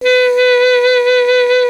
55af-sax08-B3.aif